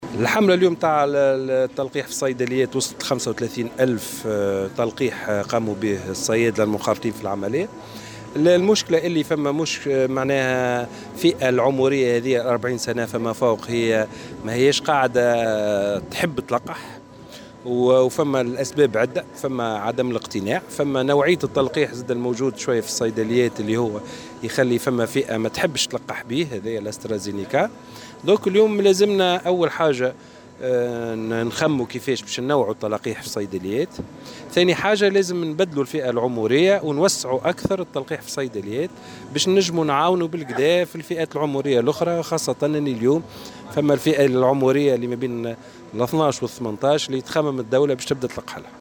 وأضاف في تصريح لمراسلة "الجوهرة أف أم" أن عدد الأشخاص الذين تم تطعيمهم في الصيدليات بلغ 35 ألف. وأوضح أنه لابد من التفكير في تنويع التلاقيح في الصيدليات وتوسيع الفئة العمرية المستهدفة، من ذلك الذين تتراوح أعمارهم ما بين 12 سنة و18 سنة والذين تفكر الدولة في إدراجهم في قائمة المنتفعين.